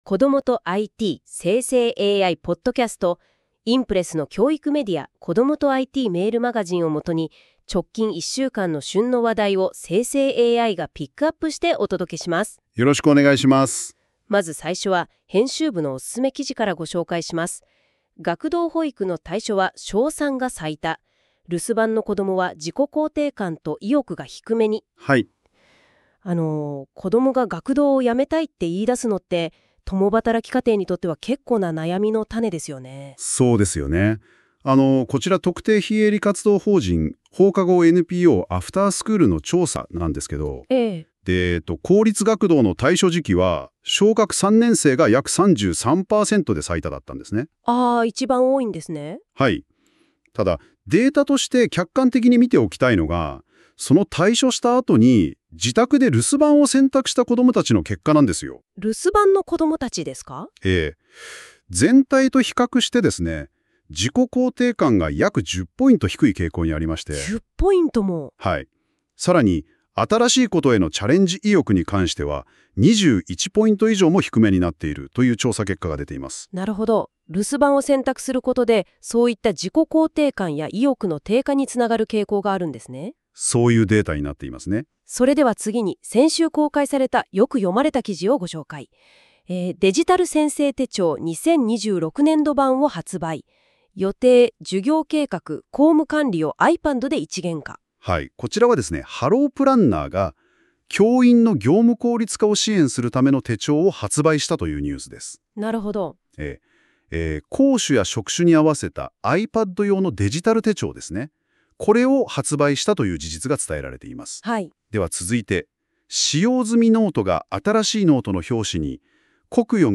※生成AIによる読み上げは、不自然なイントネーションや読みの誤りが発生します。 ※この音声は生成AIによって記事内容をもとに作成されています。